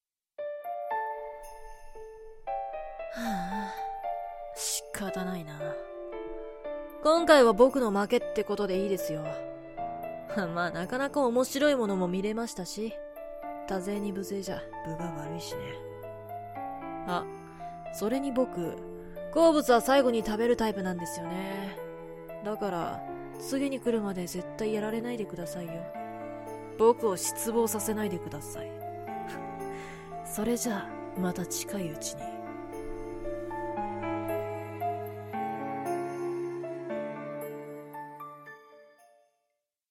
悪役少年です。